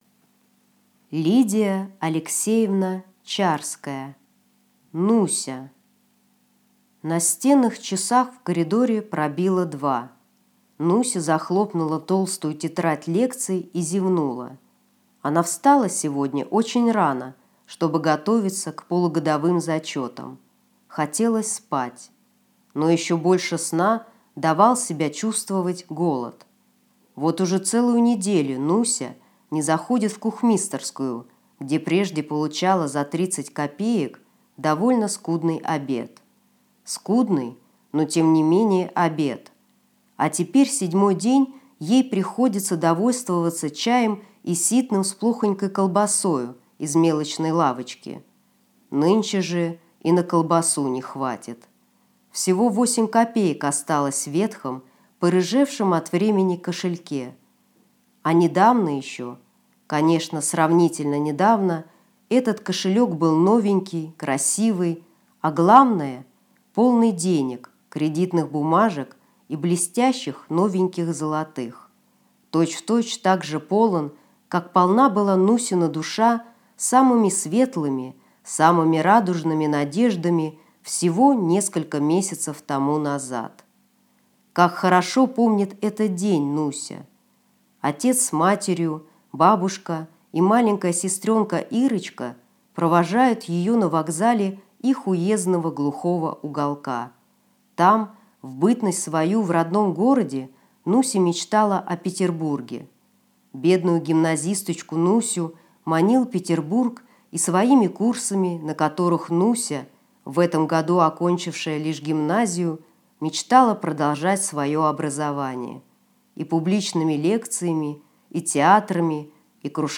Аудиокнига Нуся | Библиотека аудиокниг